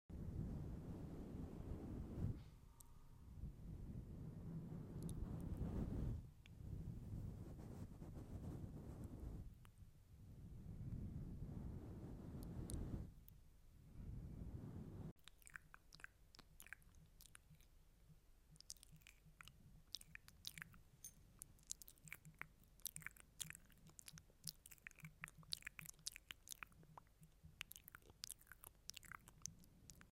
|ASMR| visual triggers 🧜🏻‍♀✋ sound effects free download